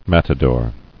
[mat·a·dor]